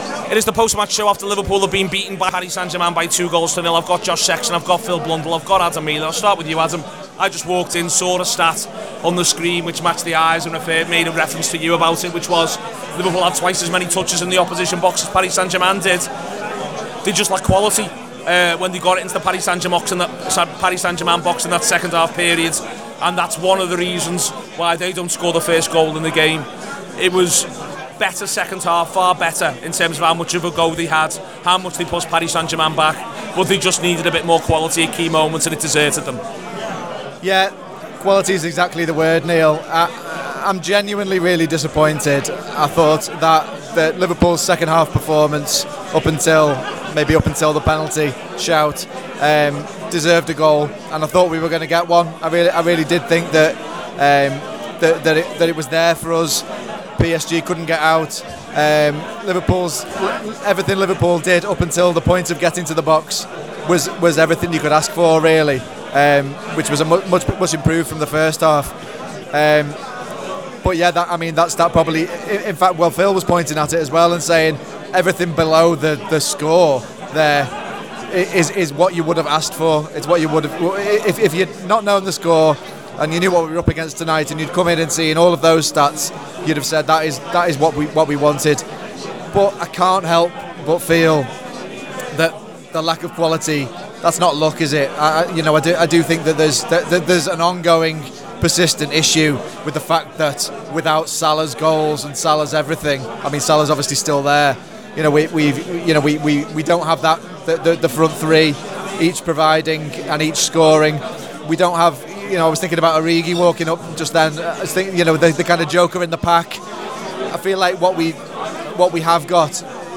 Liverpool 0- PSG 2: Post-Match Show
The Anfield Wrap’s post-match reaction podcast after fall out of the Champions League after defeat at Anfield by PSG.